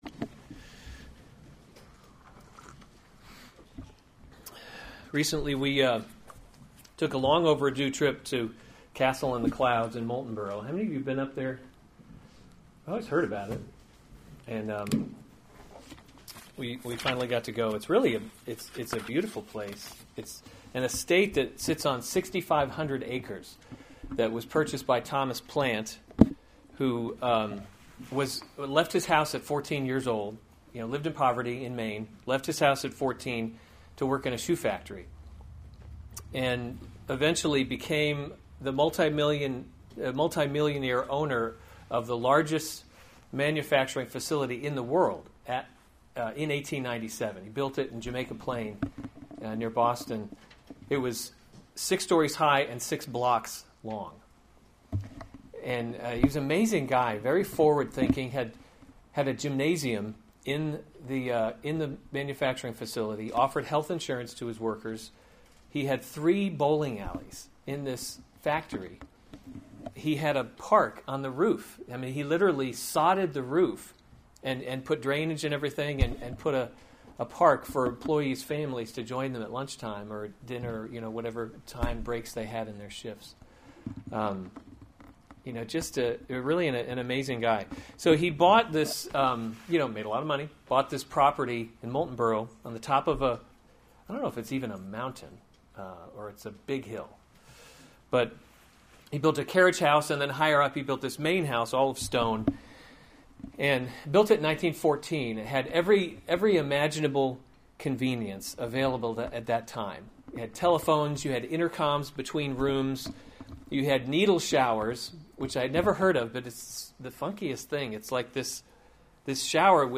July 23, 2016 Psalms – Summer Series series Weekly Sunday Service Save/Download this sermon Psalm 62 Other sermons from Psalm My Soul Waits for God Alone To the choirmaster: according […]